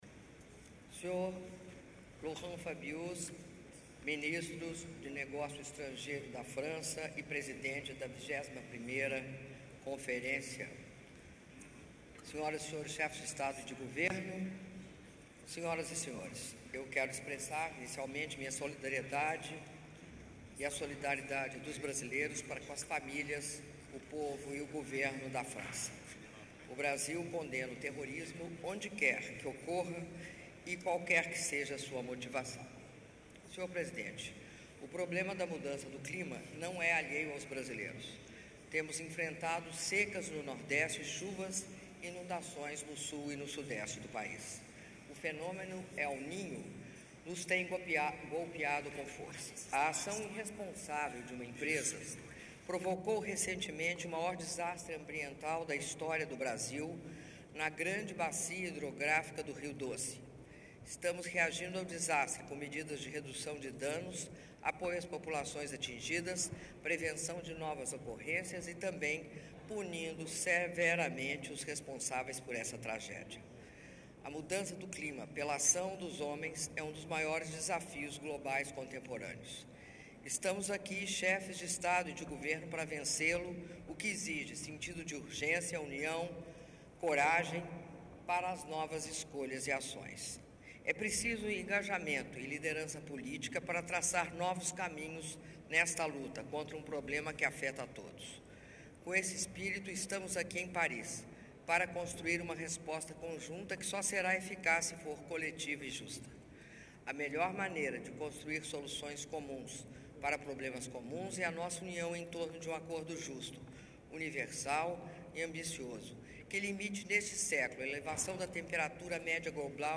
Áudio do discurso da Presidenta da República, Dilma Rousseff, durante a Sessão de Abertura da 21º Conferência das Partes da Convenção-Quadro das Nações Unidas sobre a Mudança do Clima – COP21 - Paris/França (06min59s)